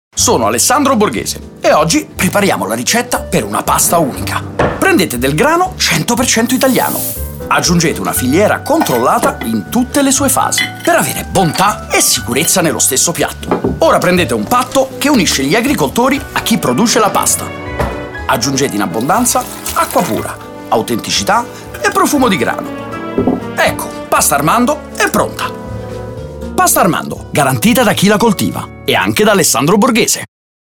La campagna radiofonica di Pasta Armando con il Brand Ambassador Chef Alessandro Borghese (al terzo anno di collaborazione con De Matteis Agroalimentare) si affida a due radiocomunicati intitolati “Favola” e “Ricetta”. Nel primo “zio Ale” tenta di raccontare una favola ai nipotini “…c’era una volta un pastificio con il suo mulino… circondato da campi di grano…”, ma i bambini scoprono subito che in realtà sta raccontando la vera storia di Pasta Armando fatta col 100% di grano italiano, buona, sicura e genuina, perché controllata in tutte le fasi della filiera e garantita da chi la coltiva, e anche da Alessandro Borghese. Nel secondo lo Chef illustra la ricetta per avere“…bontà e sicurezza nello stesso piatto…”dove gli ingredienti, sono il grano, la filiera e il patto fra agricoltori e pastificio.